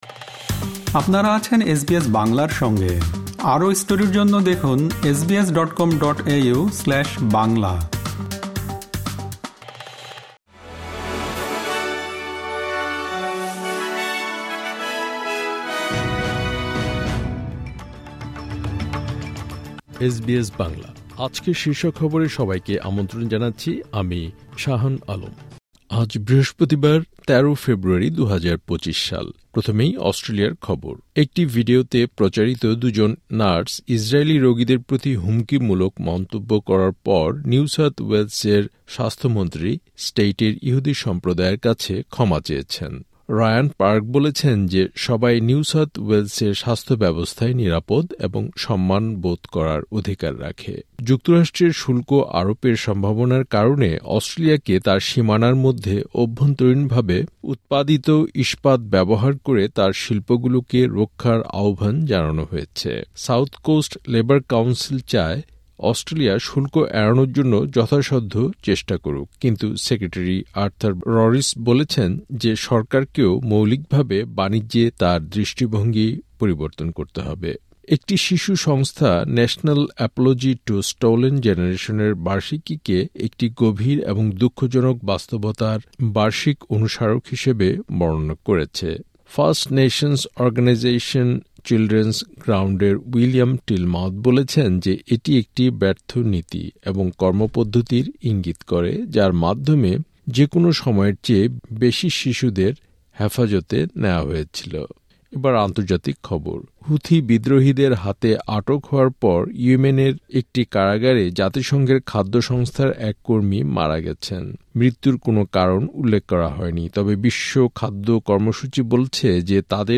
এসবিএস বাংলা শীর্ষ খবর: ১৩ ফেব্রুয়ারি, ২০২৫